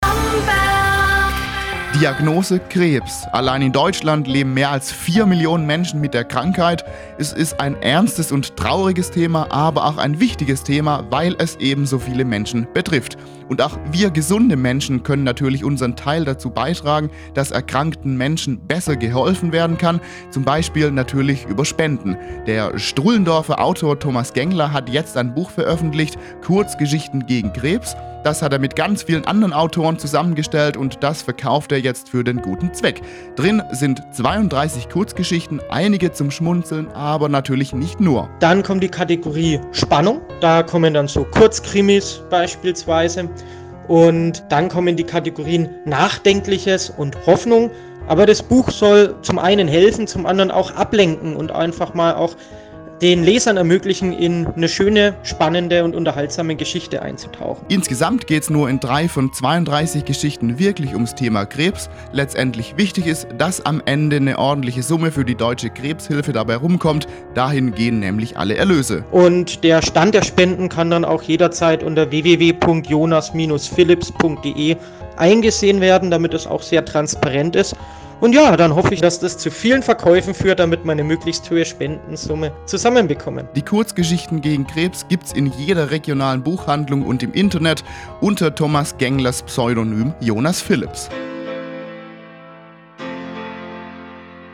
Radio−Beitrag zu Kurzgeschichten gegen Krebs (Radio Bamberg, August 2021)